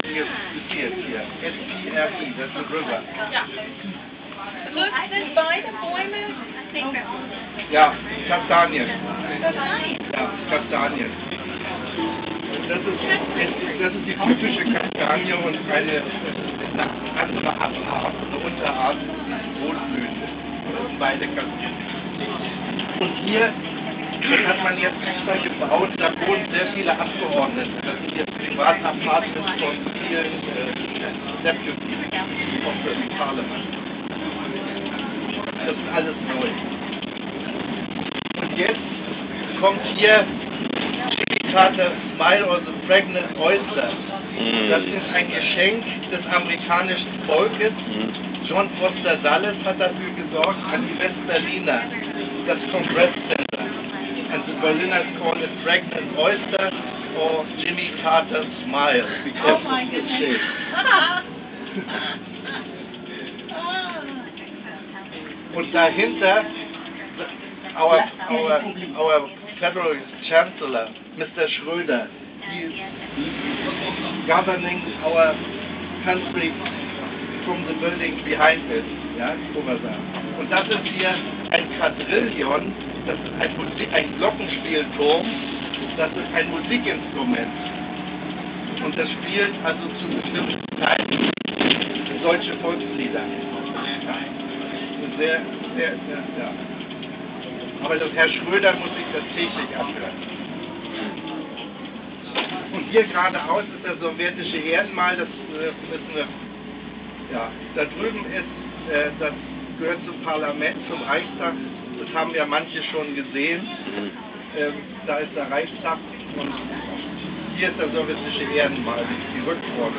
is describing Berlin from the bus.